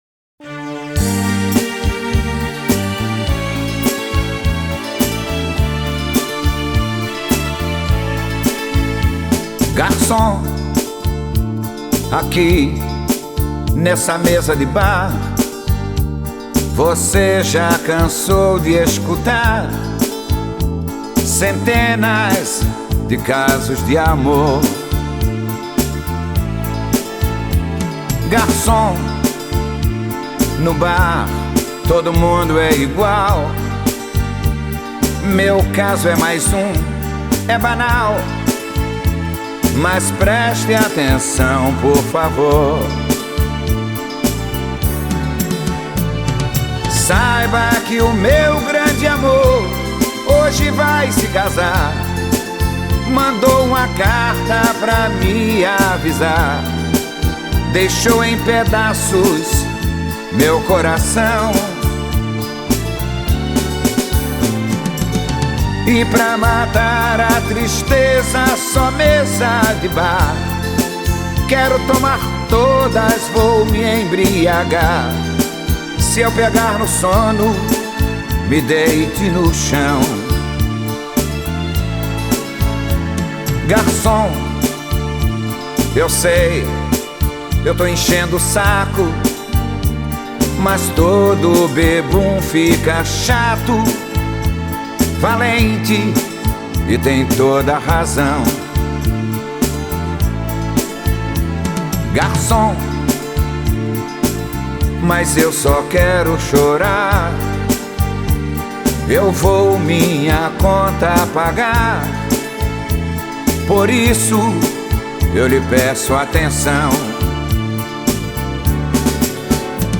2025-01-03 17:41:14 Gênero: Arrocha Views